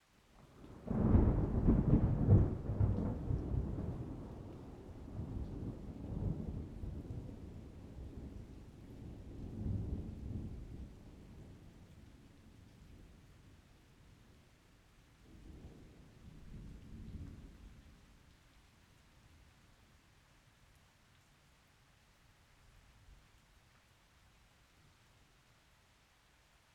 thunder-2.wav